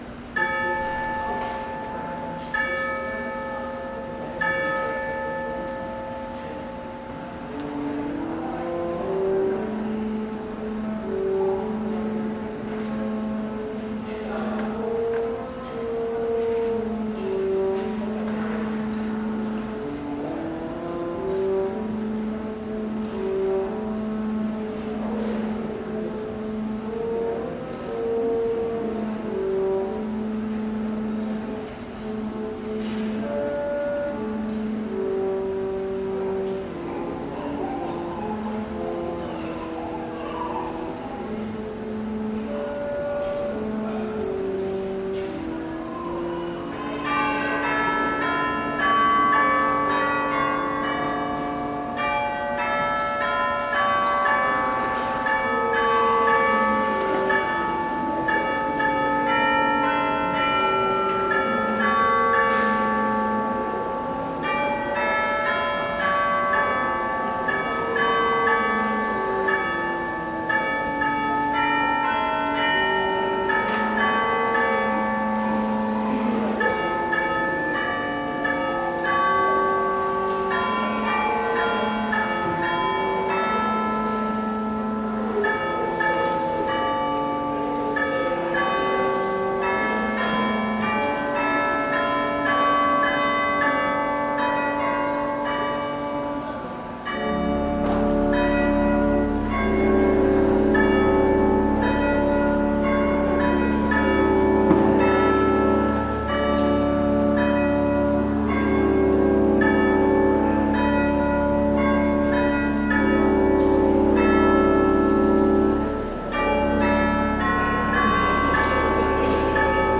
Christmas Eve Worship December 24, 2019 | First Baptist Church, Malden, Massachusetts
piano “Mary
As the light from the Christ candle is being passed, “Silent Night” is sung. Worship continues and with the lighted candles illuminating the darkened sanctuary, Scripture is read and “Joy to the World” is sung.